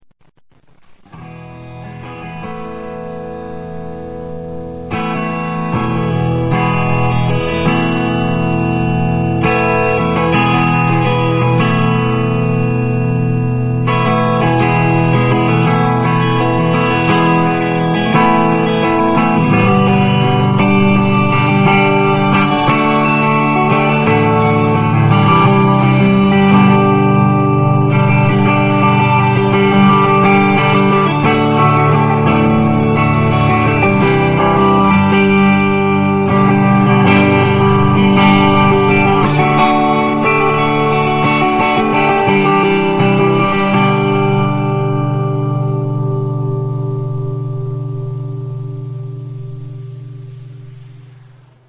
Clean and Compressed
This is the ultimate clean machine when it is dialed in correctly. The recording doesn't do it justice but in person this sounded really 3-D and surpassed some of the best tube amps I have heard. This is just me strumming a few chords in the Flextone Clean Model (Which I think sounds the best out of all of them) on the Delay/Compressor effect at about 3/4.
clean.ra